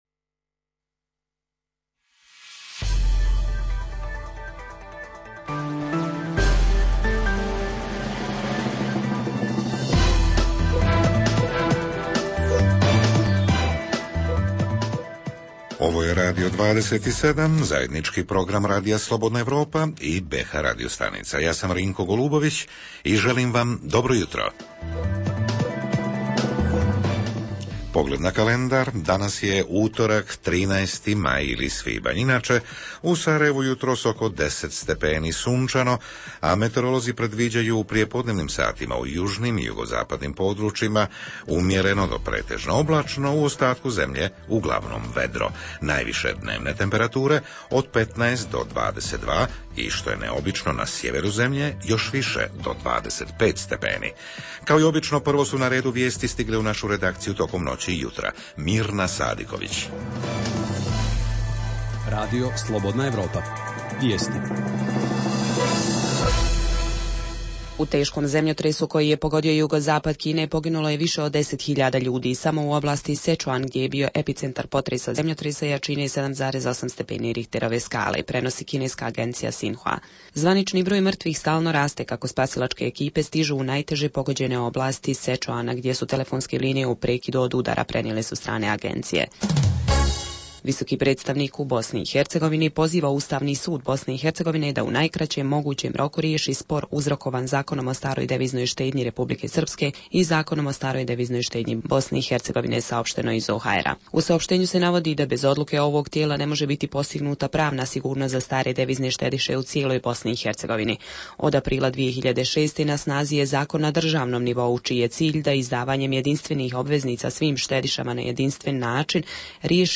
Jutarnji program za BiH koji se emituje uživo. Govorimo o socijalno ugroženim kategorijama: koliko je korisnika neke vrste pomoći (novčane , prava na topli obrok u javnim kuhinjama, subvencioniranja grijanja, naknada za vrijeme čekanja na zaposlenje…).
Redovni sadržaji jutarnjeg programa za BiH su i vijesti i muzika.